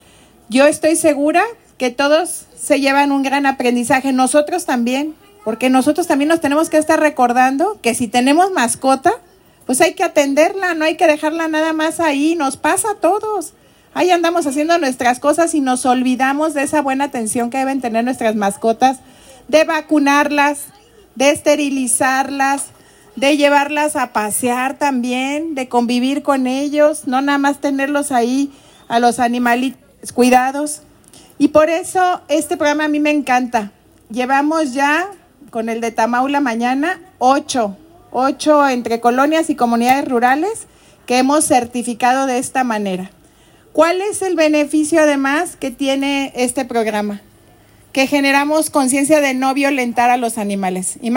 Lorena Alfaro García, presidenta municipal de Irapuato